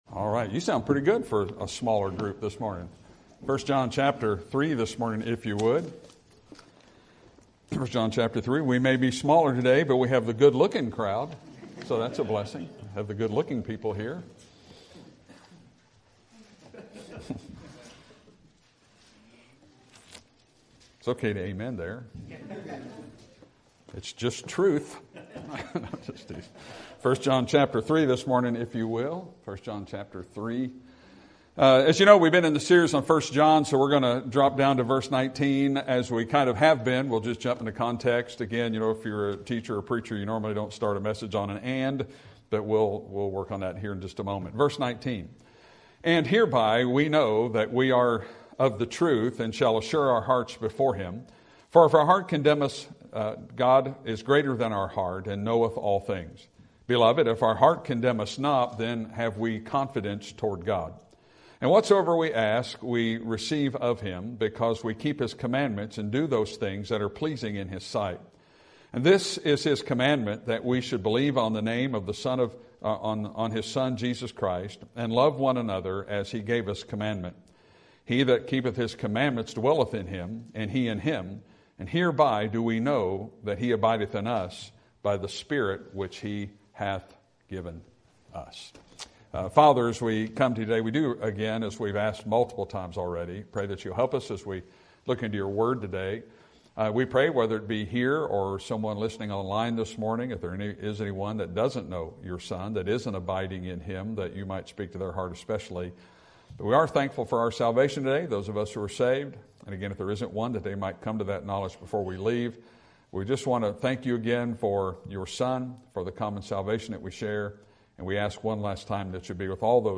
Sermon Topic: Book of 1 John Sermon Type: Series Sermon Audio: Sermon download: Download (23.35 MB) Sermon Tags: 1 John God Jesus Assurance